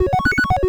retro_beeps_success_03.wav